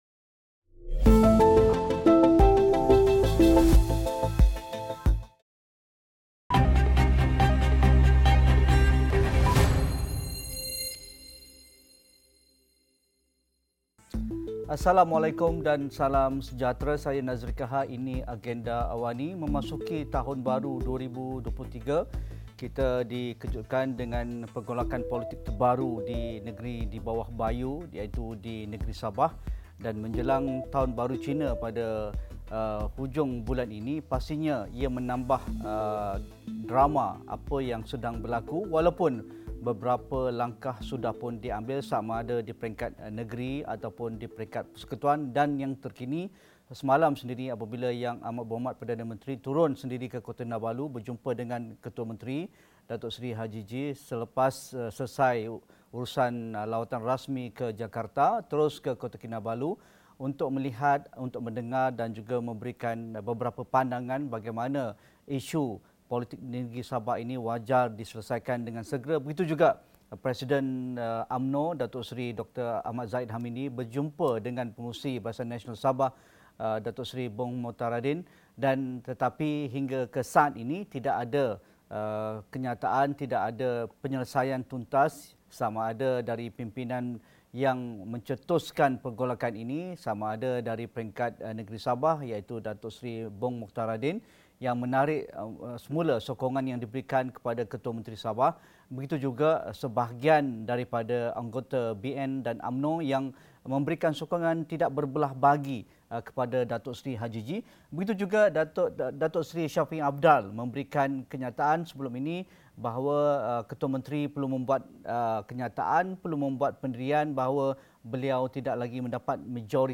Diskusi 9 malam